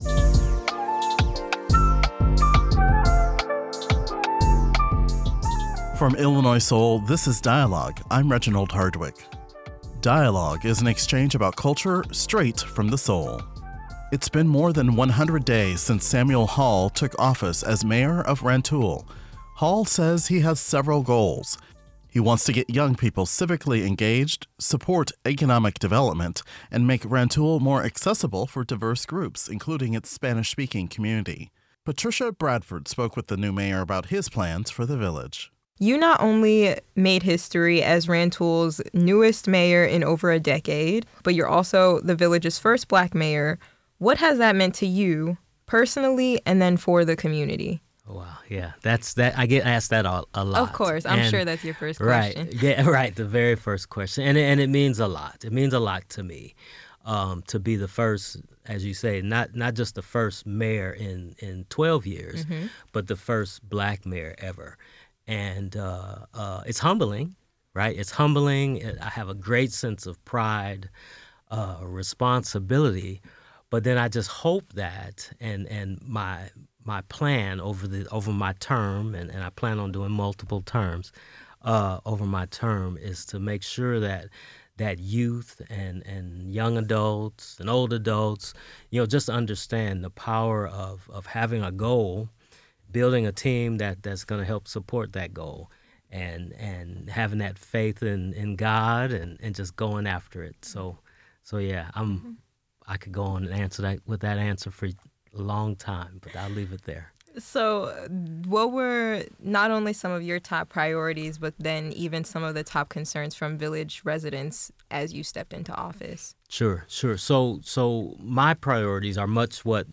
Rantoul Mayor Samuel Hall talks first 100 days, engaging with youth, supporting economic development
__ GUEST: Samuel Hall Mayor of Rantoul, Illinois